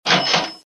Реализация: Во время хода рулетки, при прохождении профицитных скинов (относительно стоимости открытия) проигрывается короткий характерный звук - условный
cash-register.mp3